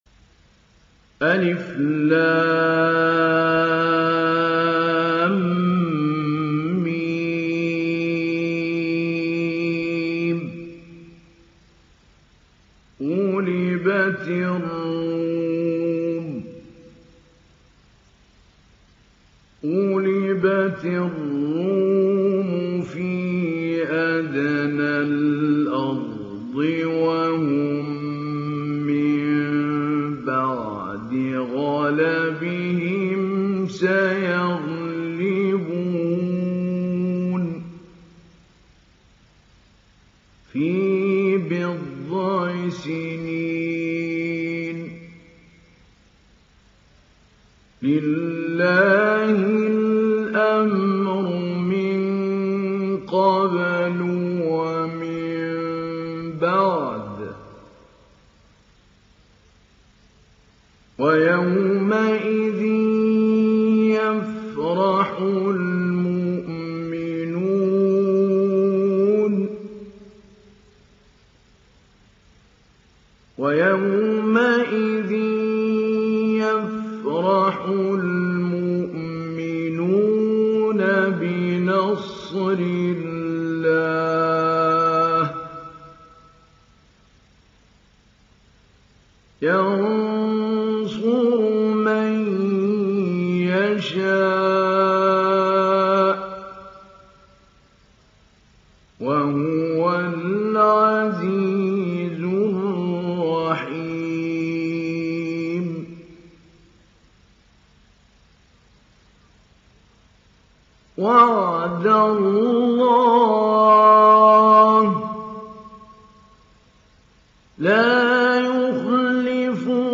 İndir Rum Suresi Mahmoud Ali Albanna Mujawwad